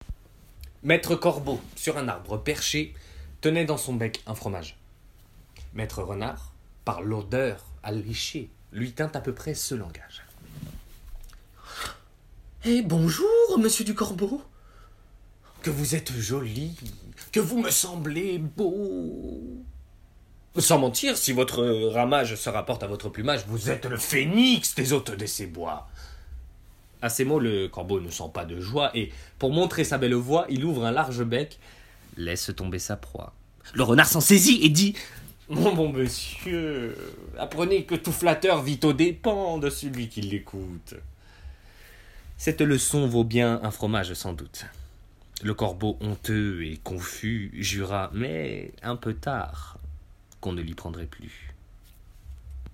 Démo Voix 1